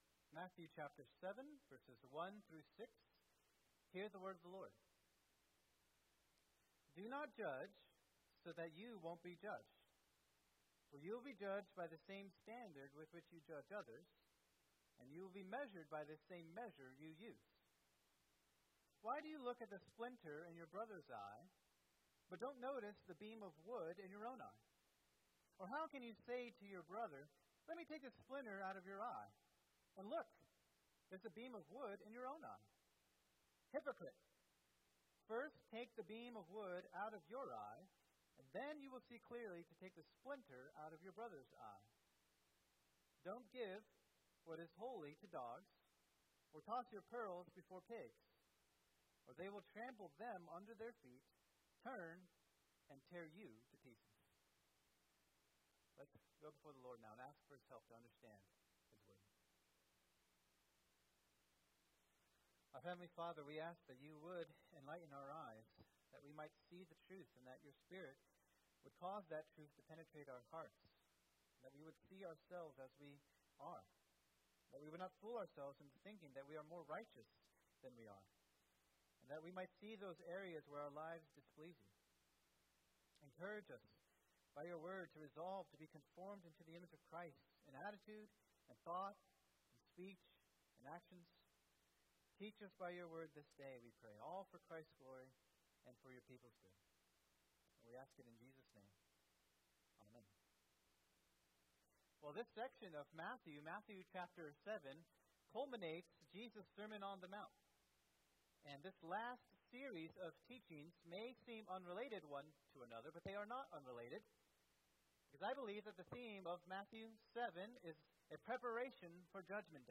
Sermon
2024 at First Baptist Church in Delphi, Indiana.